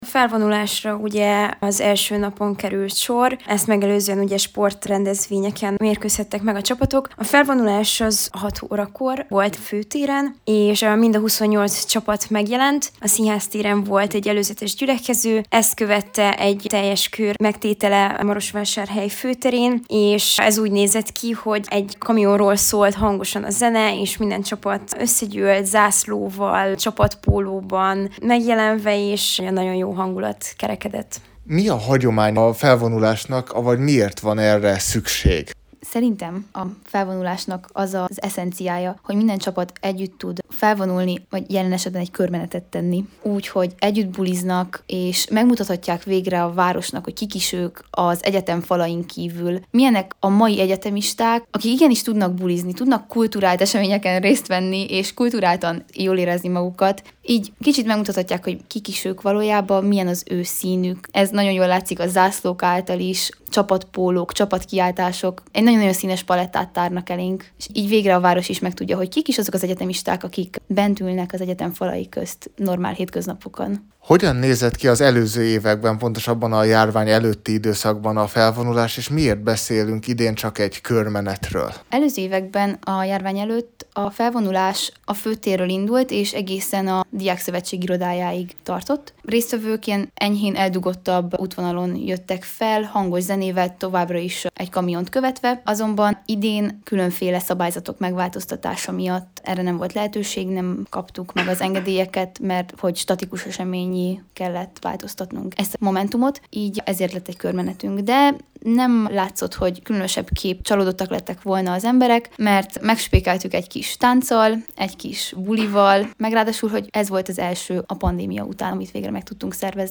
A Diáknapok kezdéséről beszélgetett felvonulást követően